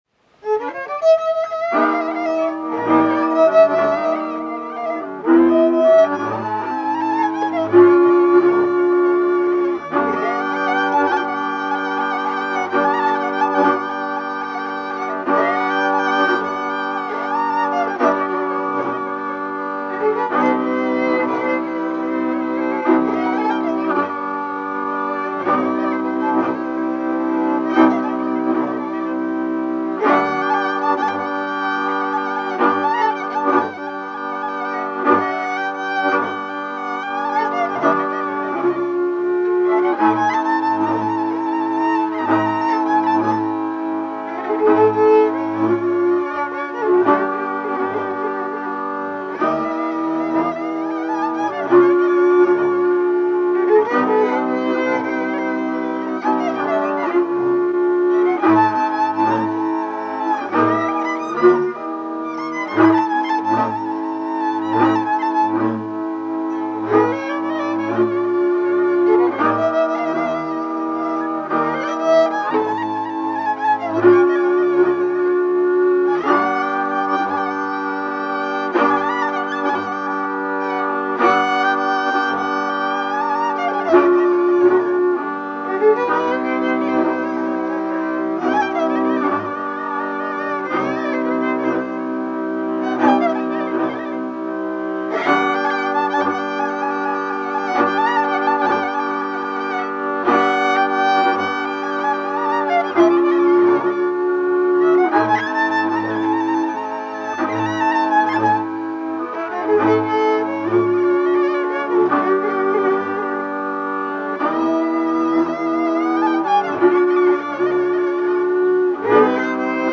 Műfaj Régies párostánc
Részl.műfaj Akasztós
Hangszer Zenekar
Helység Visa
Cím Visa. Észak-mezőségi magyar népzene. Archív felvételek Kallós Zoltán gyűjtéséből